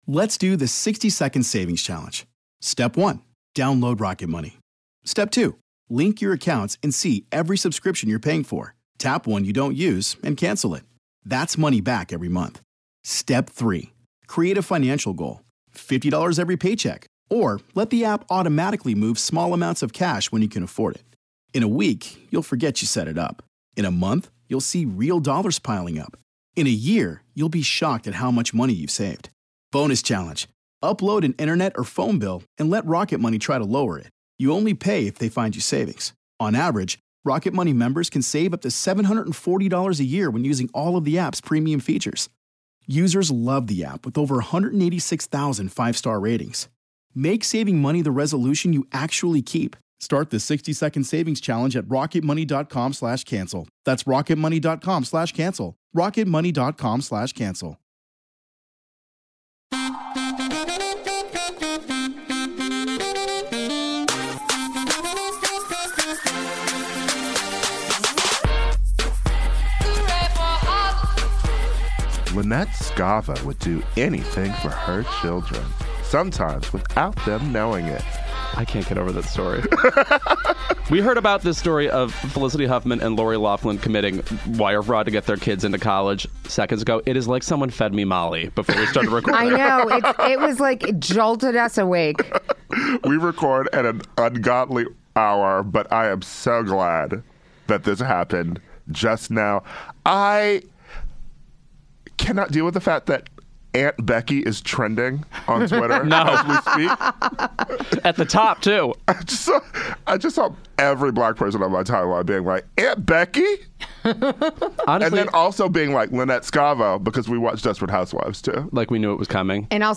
Special guest Mandy Moore joins to talk about her music and acting career! Plus, Felicity Huffman and Lori Laughlin are in embroiled in a college bribing scandal, Pete Davidson pulls a Sinead O’Connor on SNL, Showtime axes SMILF due to creator Frankie Shaw’s alleged misconduct on set, and more!